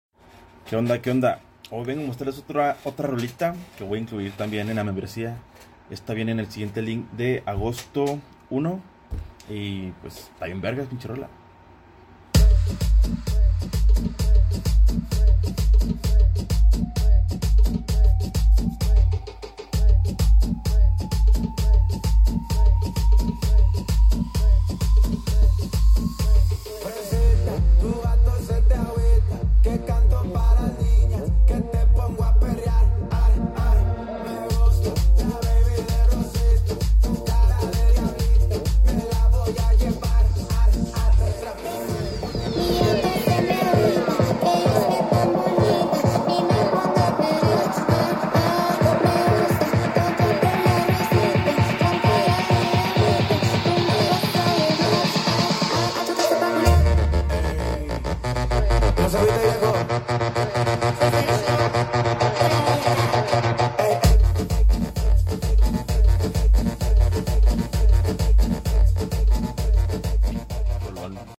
Guaracha Bootleg